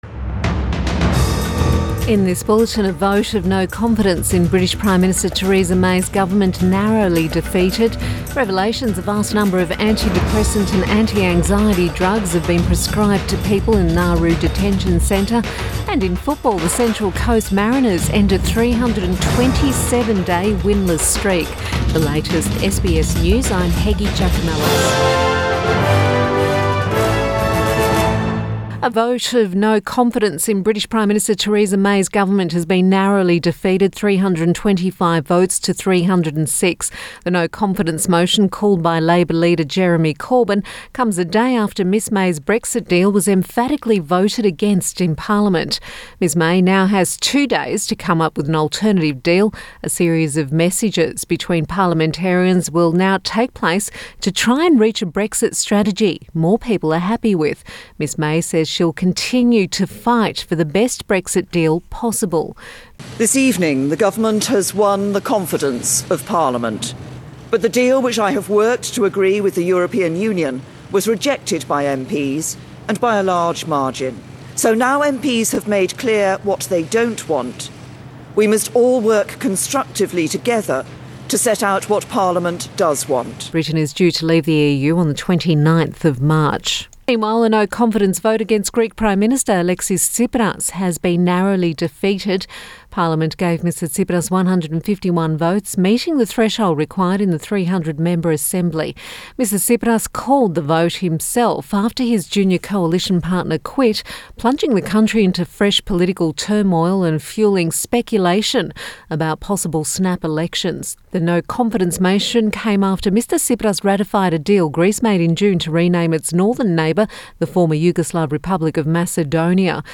Midday Bulletin Jan 17